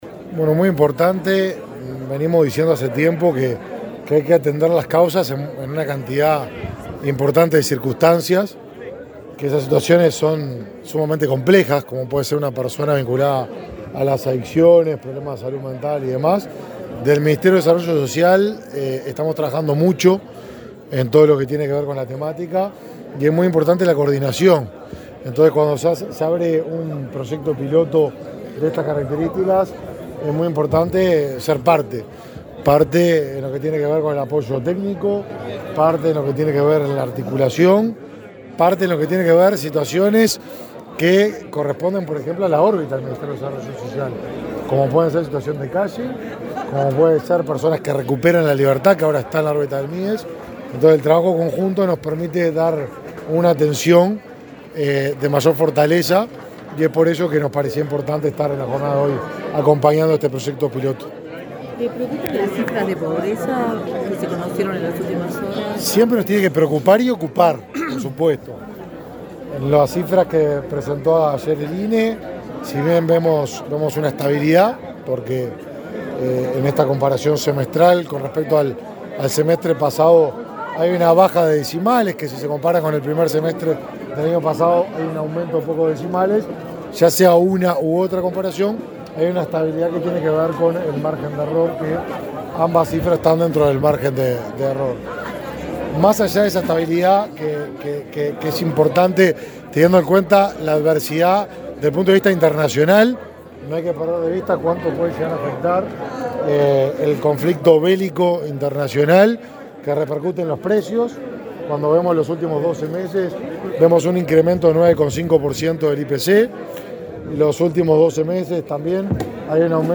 Declaraciones a la prensa del ministro de Desarrollo Social, Martín Lema
La Intendencia de Maldonado y la Administración de los Servicios de Salud del Estado implementan un plan piloto de prevención y tratamiento de adicciones e instalaron un centro a tales fines. Al acto inaugural asistió el ministro de Desarrollo Social, Martín Lema, quien dialogó con la prensa sobre temas de interés para la cartera que dirige.